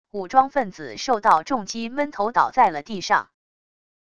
武装分子受到重击闷头倒在了地上wav音频